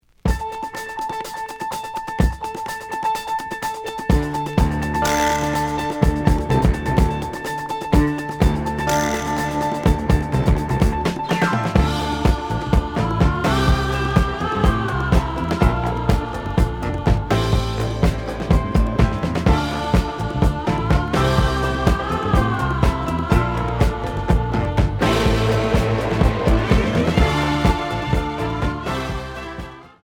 試聴は実際のレコードから録音しています。
●Format: 7 inch
●Genre: Disco